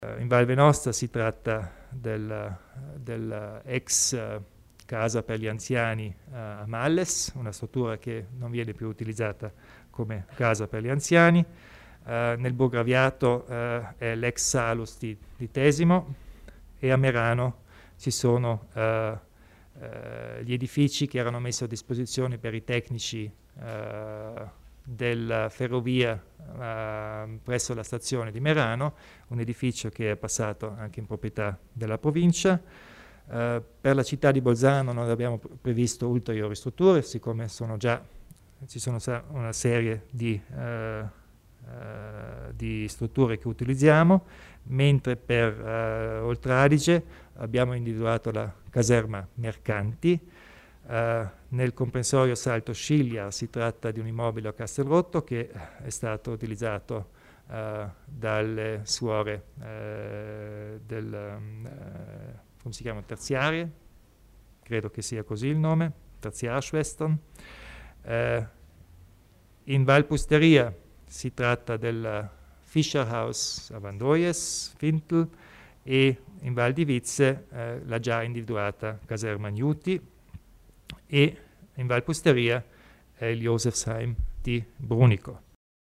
Il Presidente Kompatscher illustra le novità in tema di accoglienza profughi